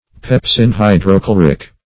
Search Result for " pepsin-hydrochloric" : The Collaborative International Dictionary of English v.0.48: Pepsinhydrochloric \Pep`sin*hy`dro*chlo"ric\, a. (Physiol.
pepsin-hydrochloric.mp3